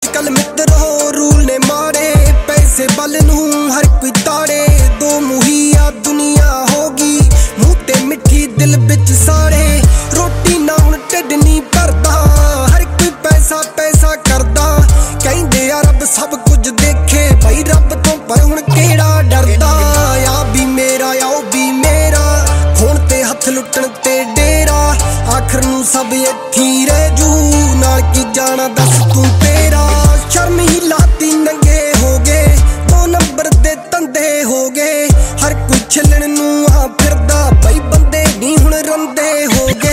PUNJABI SONG RINGTONE for your mobile phone in mp3 format.